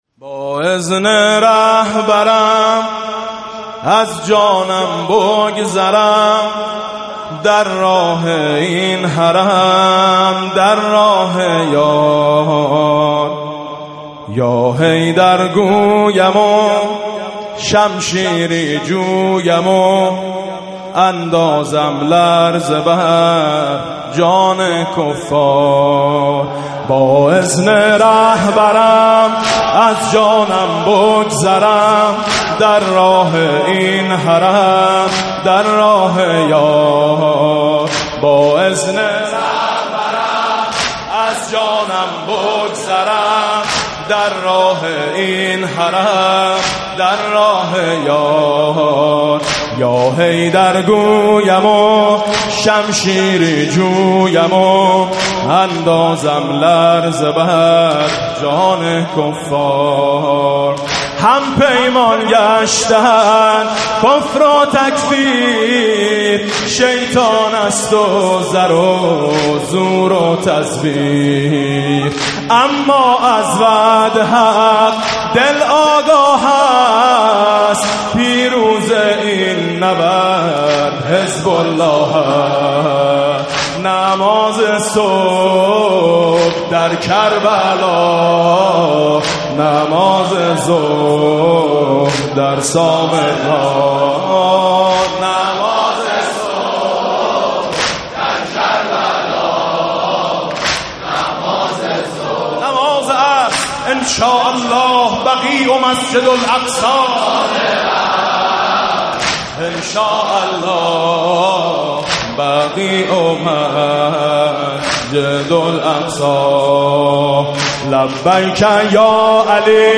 نوحه
مداحی پر شور